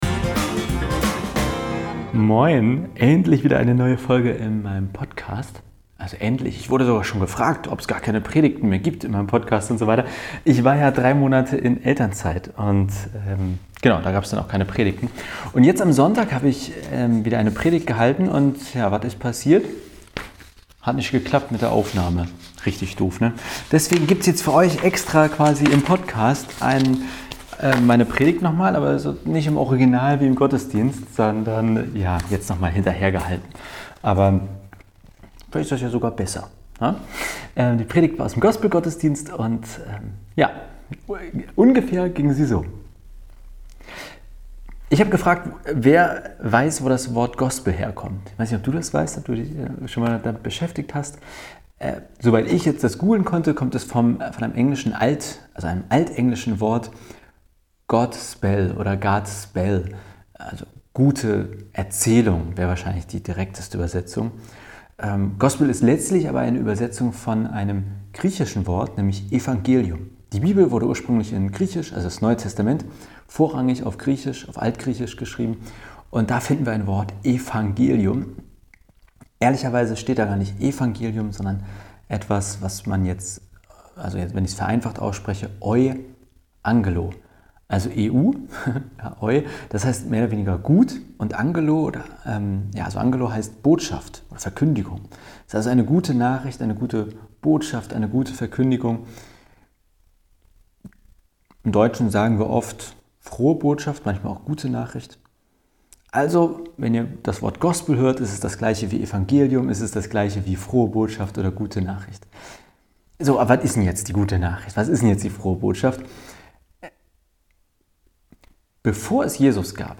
Meine erste Predigt nach meiner Eltern-/Urlaubszeit – aus unserem Gospelfestival-Gottesdienst. Entsprechend geht es um die Frage, was das Wort „Gospel“ eigentlich heißt, wo es herkommt und was für ein Inhalt sich dahinter verbirgt!